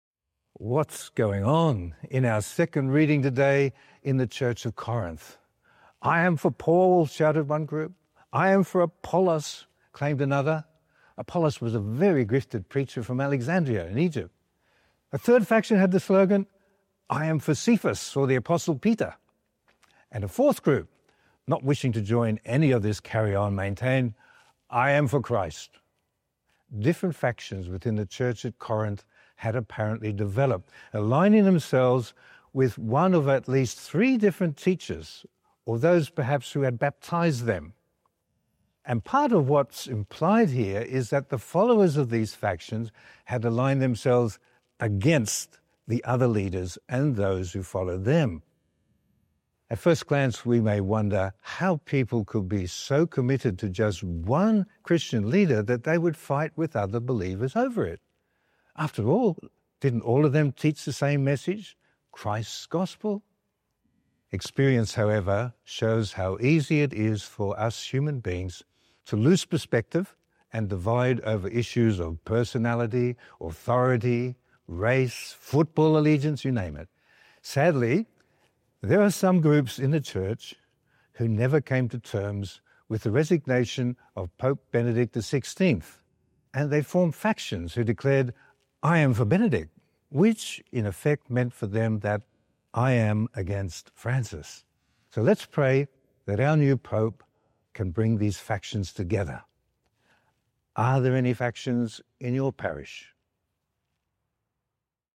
Archdiocese of Brisbane Third Sunday in Ordinary Time - Two-Minute Homily